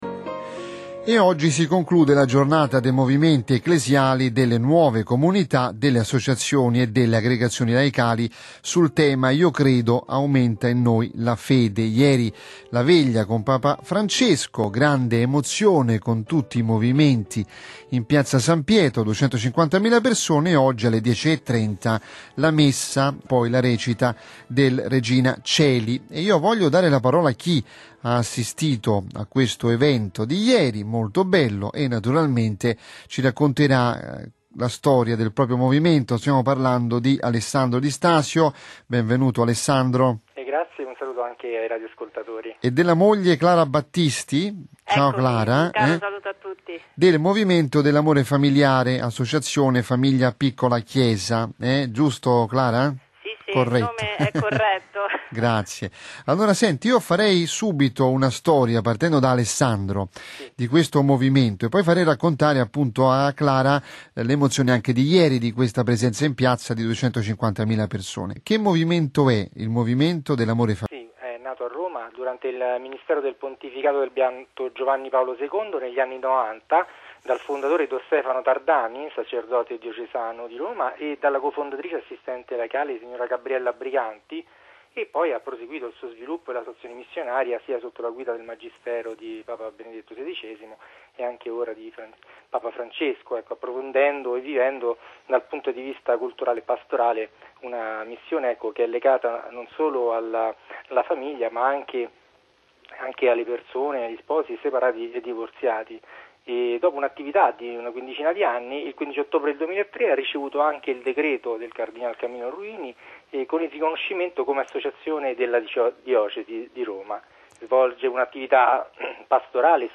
testimonianza di una coppia di catechisti sulla partecipazione all’evento e sul significato di questo incontro per l’opera di evangelizzazione dei Movimenti e Associazioni.